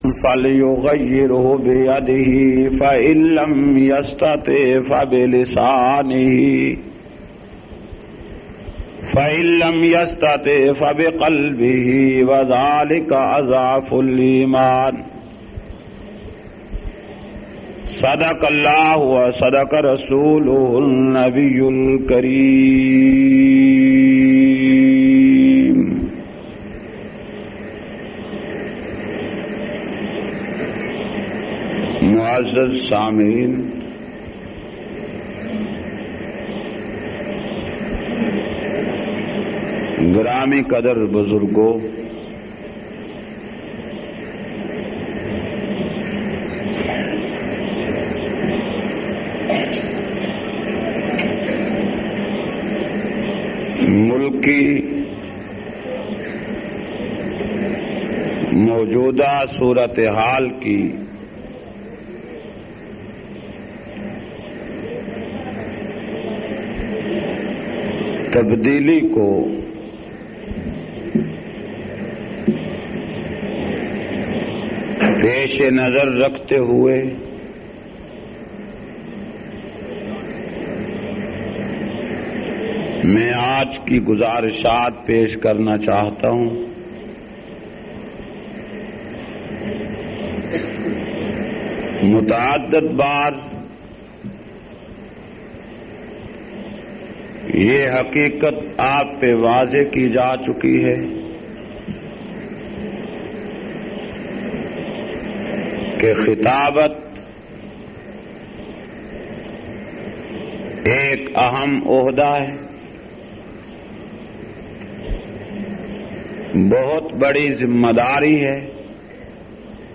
321- Mulki Siyasat aur Islami Nizam Jumma Jhang.mp3